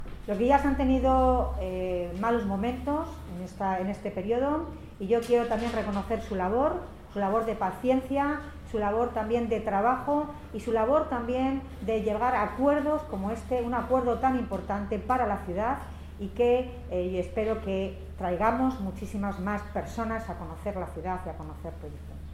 Palabras de Milagros Tolón en una atención a los medios de comunicación tras la firma del convenio de colaboración entre el Ayuntamiento de Toledo, Puy du Fou España y la Confederación Española de Federaciones y Asociaciones de Guías Turismo (CEFAPIT) en el que se sientan las bases para hacer de Toledo la ciudad patrimonio más competitiva de Europa en materia de turismo sostenible y experiencial.